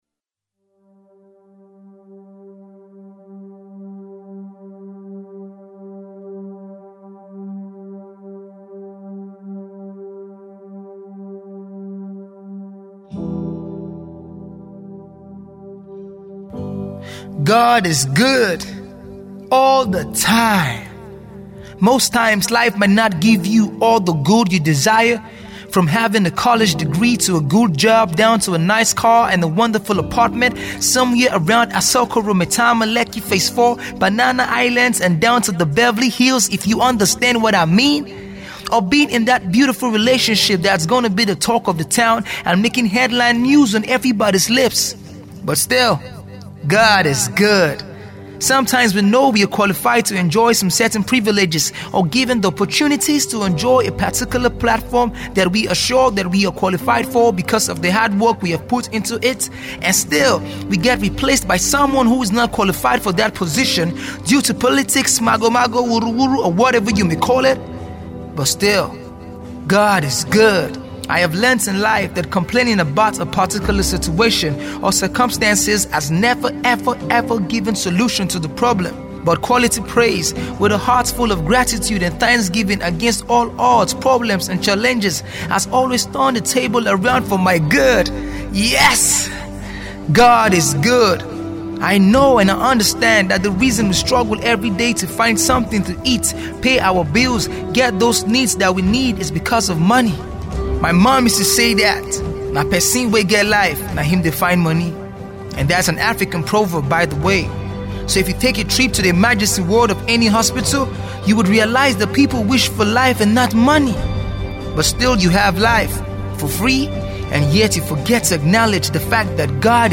Spoken Word artist
inspirational and motivational piece